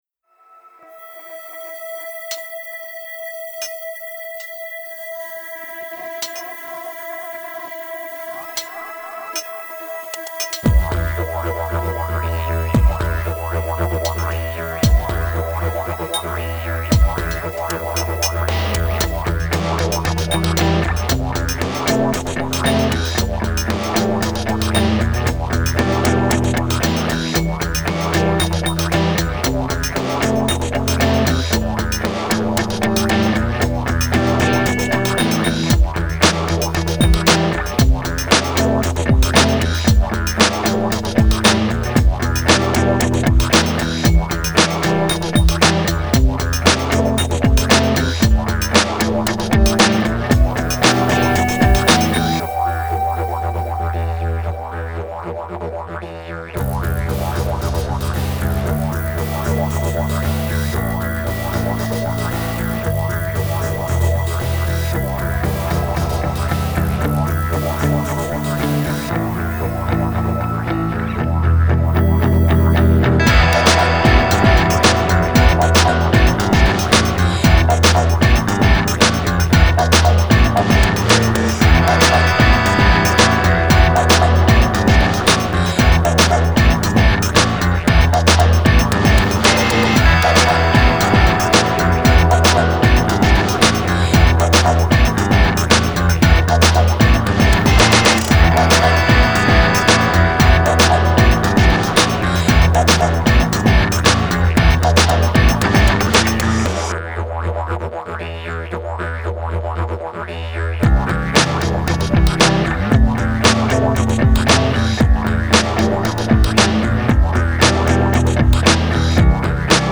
Bon donc ça faisait longtemps, une compo avec toujours autant de merdier autour du didge (guitare, basse, programmation), là j'ai pris le parti de garder le même rythme au didge, et de le laisser filer tout le long du morceau, avec juste l'apparition de cris à certains endroits!
je kifff la poulalala ! le son en plus est pas mal travaillé ! huhuhuh
Putain le rif de gratte du départ ça part déjà énorme soutenu par la tourne de didj hyper dynamique, et là bim la batterie qui fracasse avec une grosse caisse claire qui tabasse.
Puis là hop, break et on se retrouve dans une une ambiance dubisante sombre juste comme il faut, l'atmosphère change complètement.
Enfin bref, outre cette petite réserve, j'aime beaucoup comme d'hab et le son est vraiment excellent.